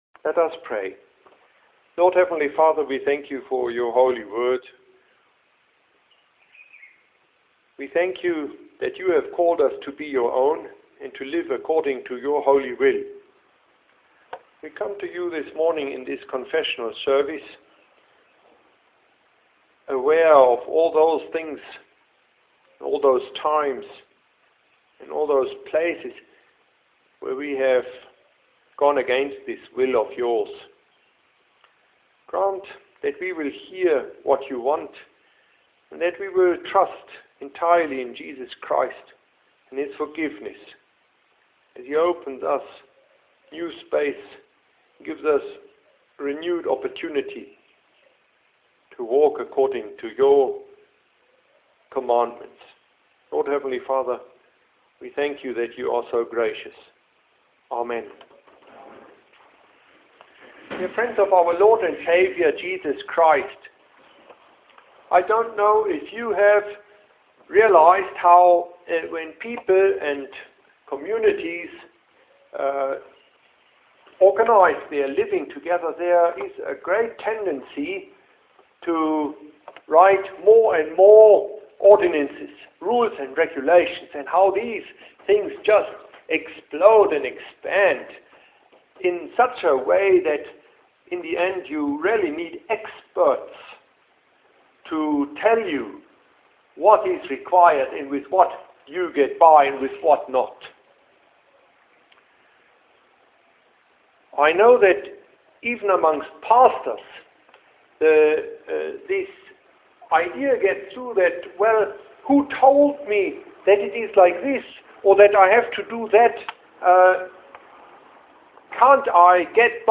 Here is this mornings confessional address based on Colossians 3:12-17